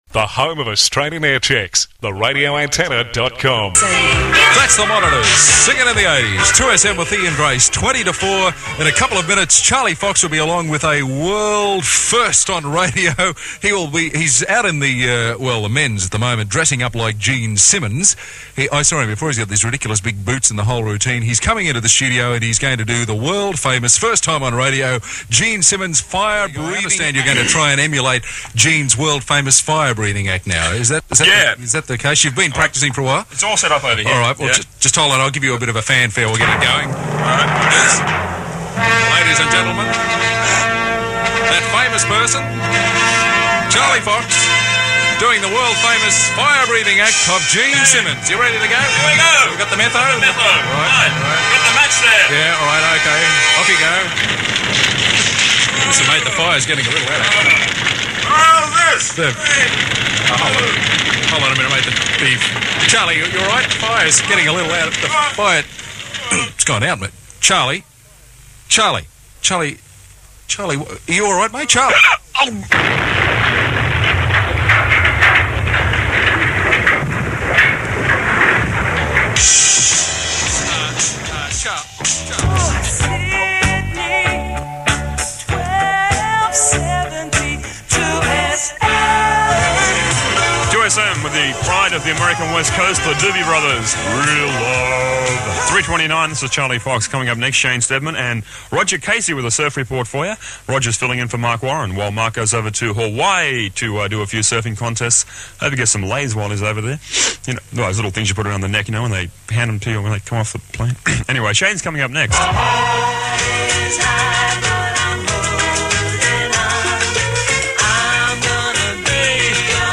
ahh radio in the late 70’s early 80’s